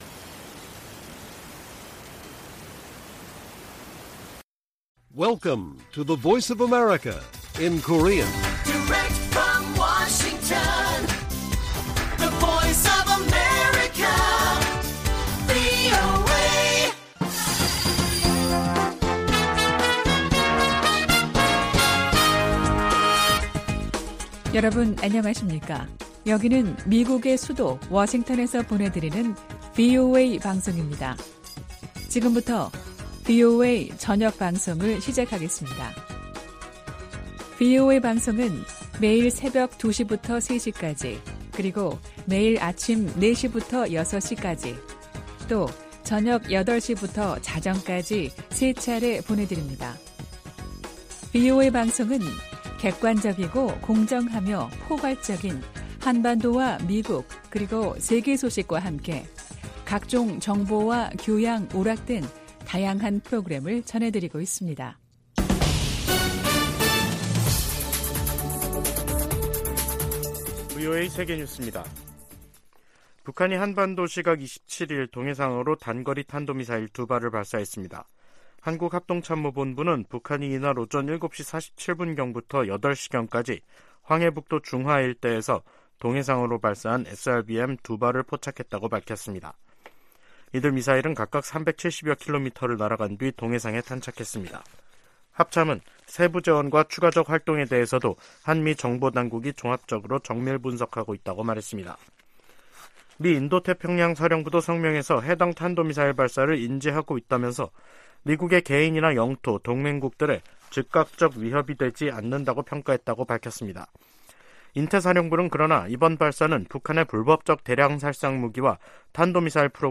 VOA 한국어 간판 뉴스 프로그램 '뉴스 투데이', 2023년 3월 27일 1부 방송입니다. 북한이 또 다시 단거리 탄도미사일(SRBM) 두 발을 동해상으로 발사했습니다. 미 국방부는 북한의 수중 핵폭발 시험에 대해 우려를 나타내고, 한국과 다양한 훈련을 계속 수행할 것이라고 밝혔습니다. 미 공화당 중진 상원의원이 한국에 핵무기를 재배치하는 방안을 고려해야 한다고 주장했습니다.